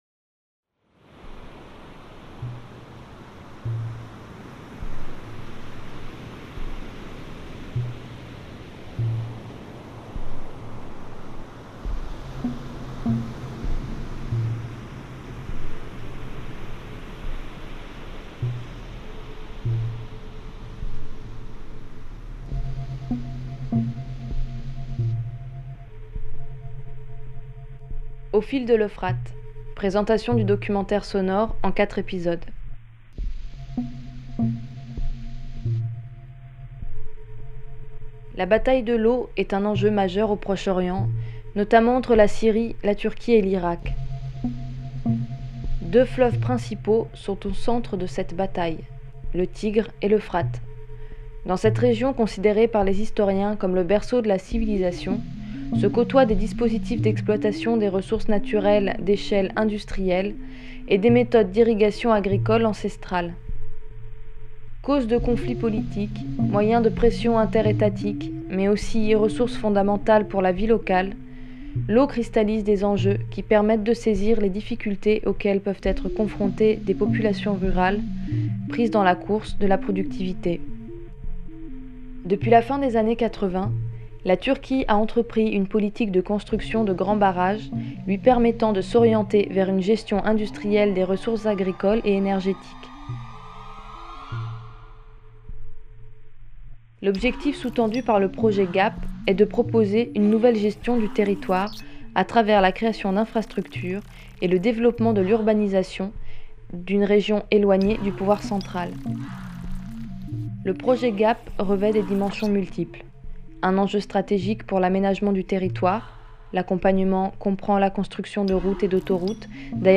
Présentation de la série documentaire.
On rencontrera ensuite trois personnes venant de Dersim, une province aux grandes particularités culturelles et sociales où vivent les alevi.